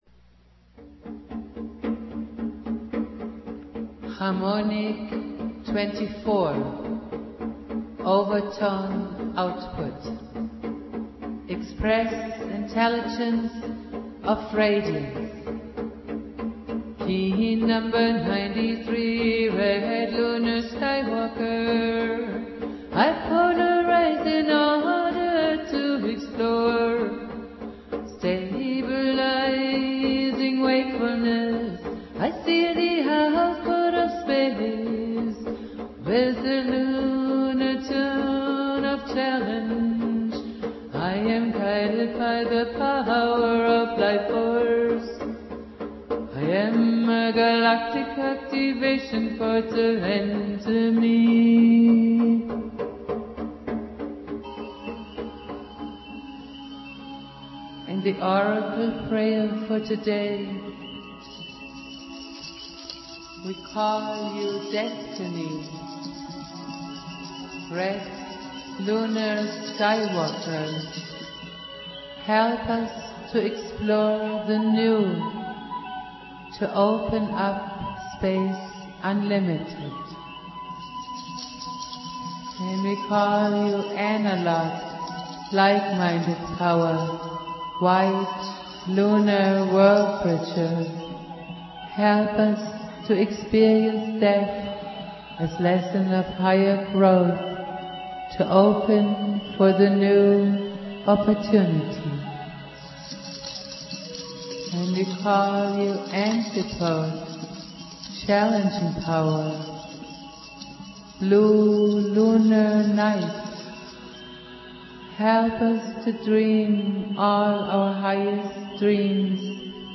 Valum Votan playing flute.
Prayer